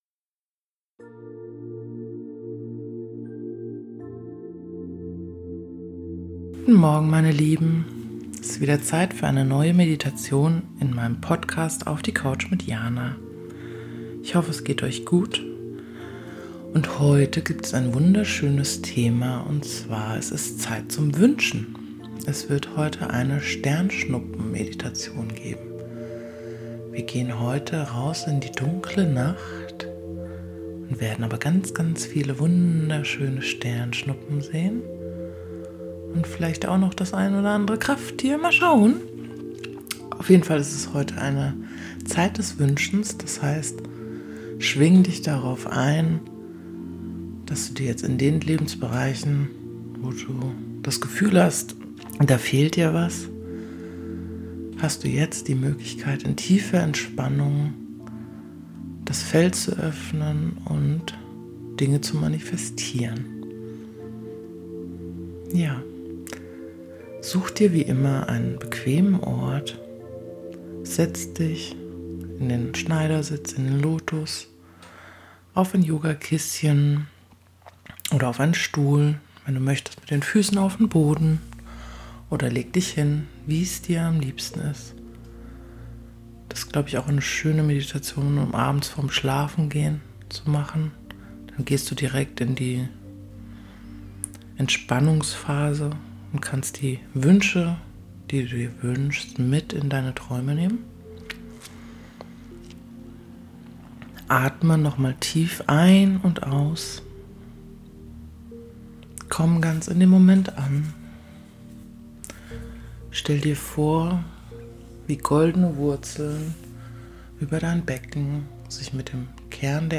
Heute ist es Zeit zum Wünschen :-) In der heutigen Meditation bekommst Du den Zugang zu der Weite des Universums und hast 3 Wünsche frei.